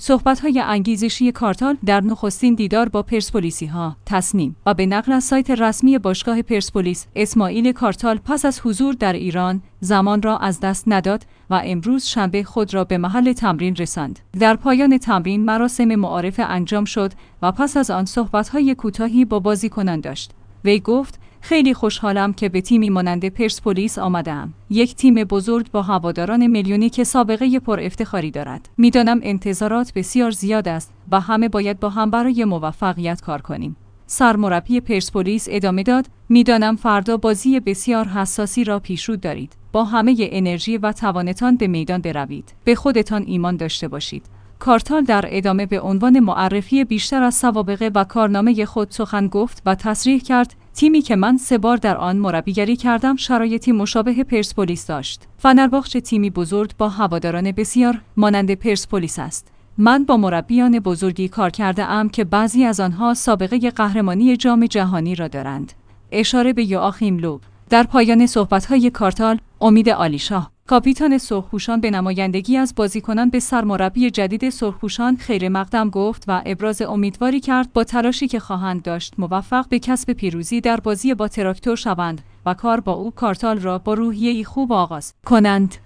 صحبت‌های انگیزشی کارتال در نخستین دیدار با پرسپولیسی‌ها
در پایان تمرین مراسم معارفه انجام شد و پس از آن صحبت‌های کوتاهی با بازیکنان داشت.